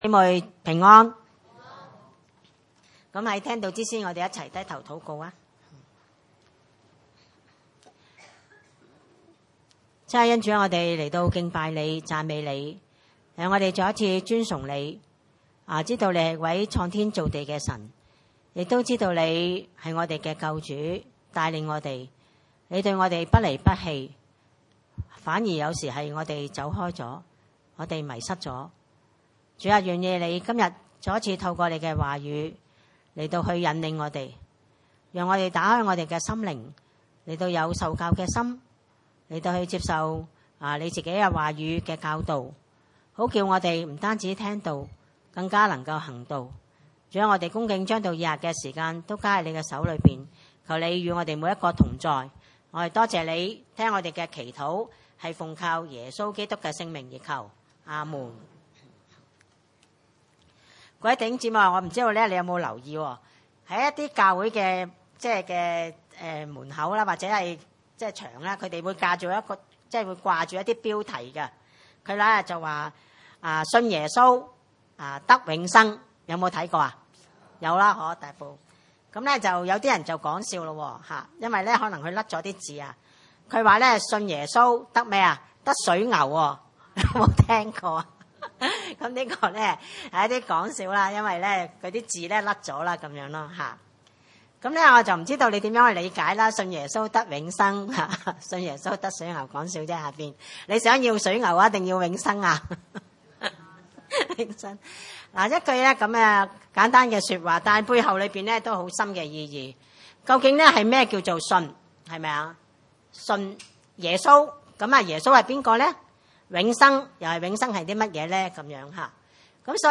經文: 雅各書2 : 14-26 崇拜類別: 主日午堂崇拜 14我的弟兄們，若有人說自己有信心，卻沒有行為，有什麼益處呢？